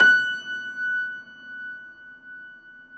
53c-pno20-F4.wav